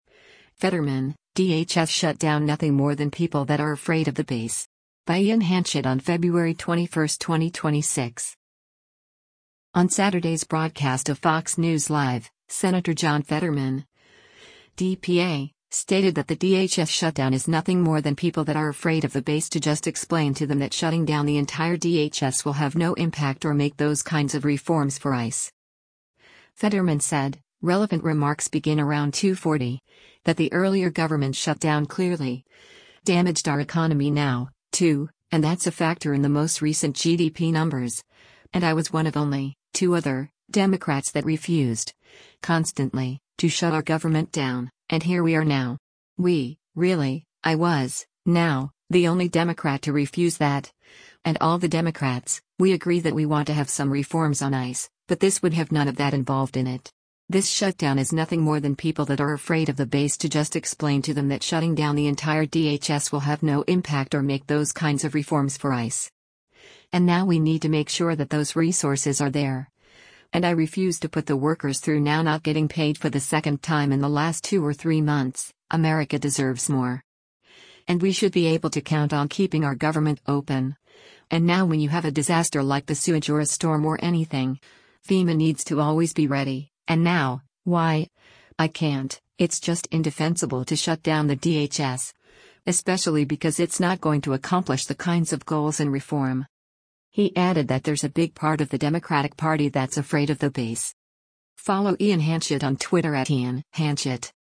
On Saturday’s broadcast of “Fox News Live,” Sen. John Fetterman (D-PA) stated that the DHS shutdown “is nothing more than people that are afraid of the base to just explain to them that shutting down the entire DHS will have no impact or make those kinds of reforms for ICE.”